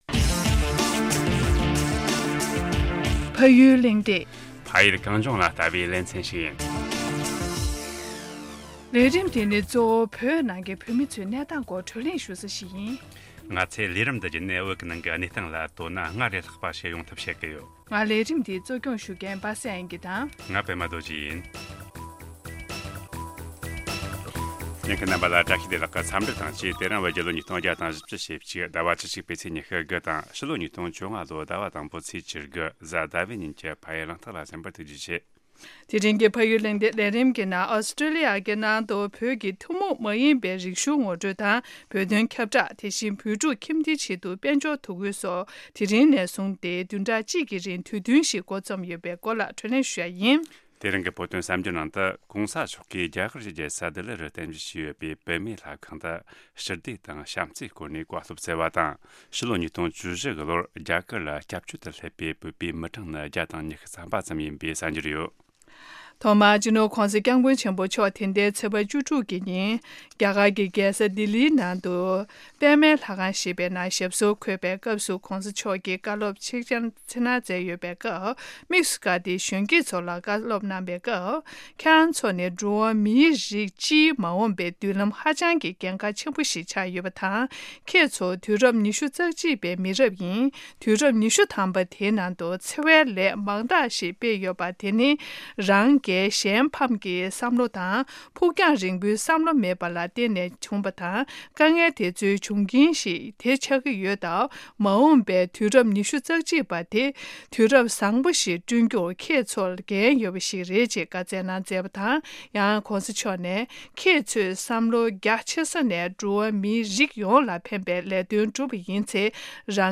བགྲོ་གླེང་ཞུས་པ་ཡིན།།